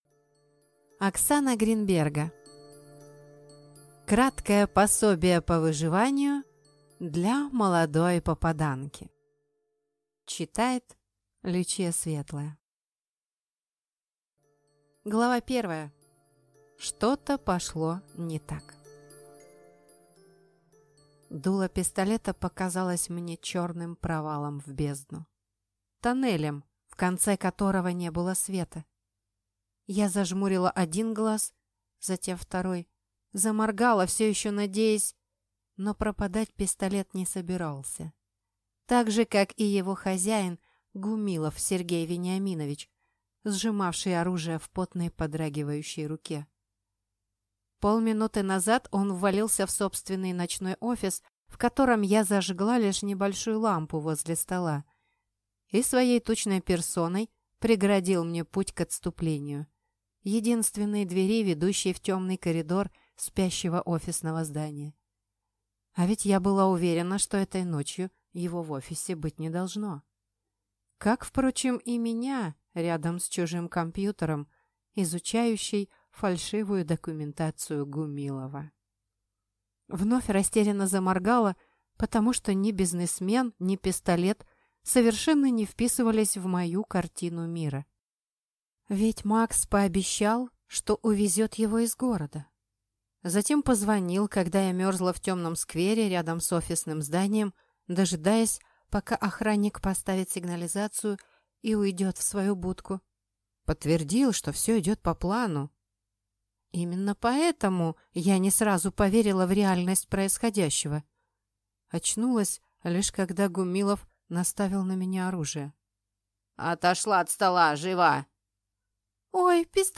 Аудиокнига Краткое пособие по выживанию для молодой попаданки | Библиотека аудиокниг